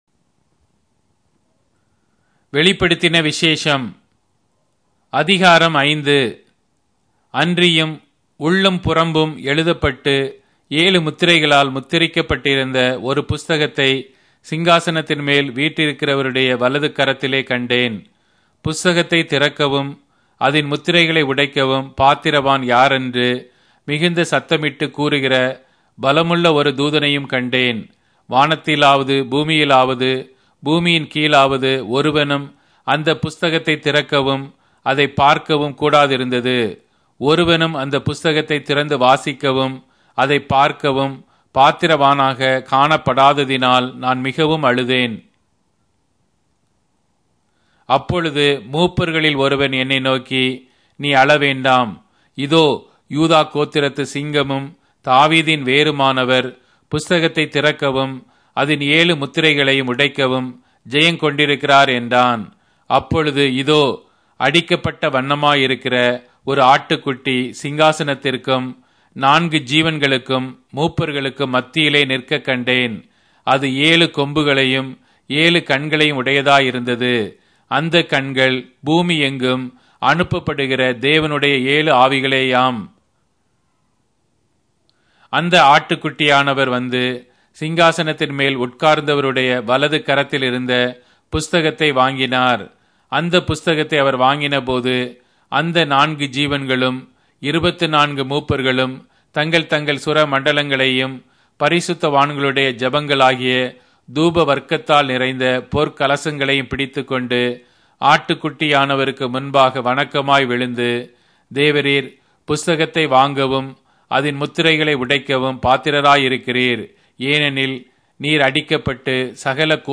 Tamil Audio Bible - Revelation 12 in Tev bible version